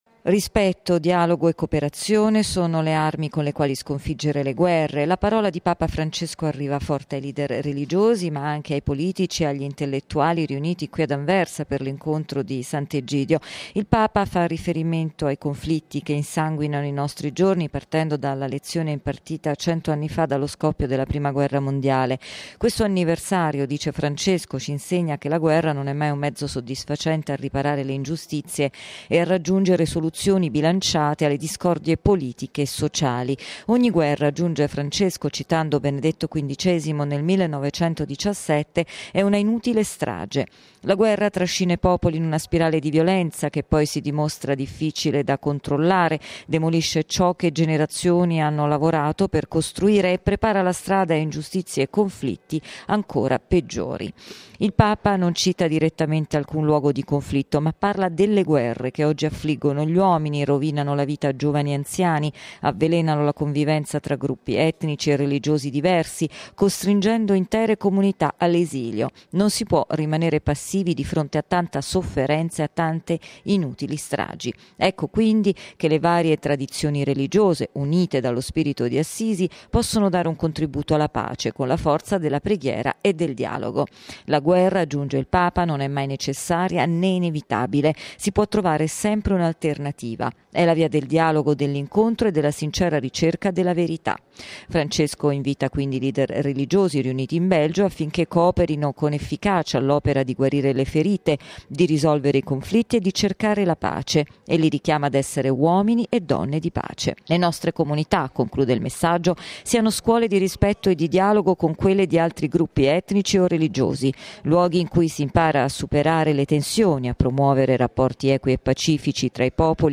La nostra inviata